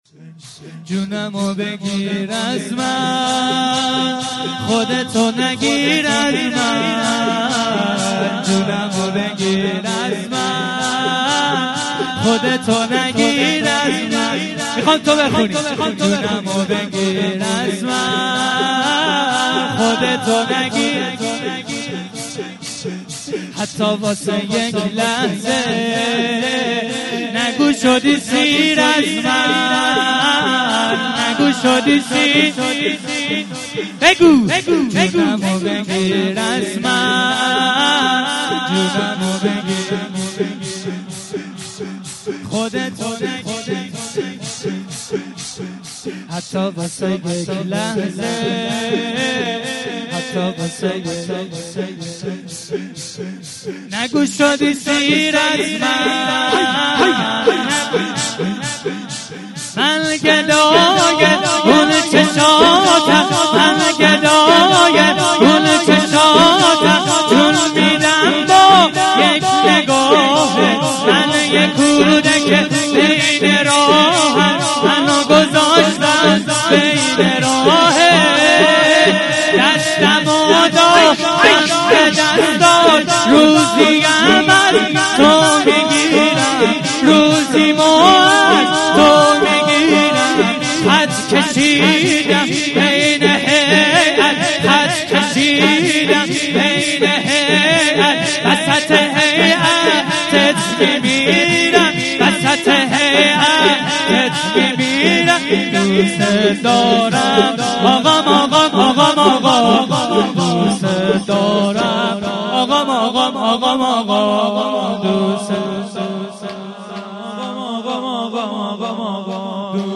شور- جونم و بگیر از من 03.mp3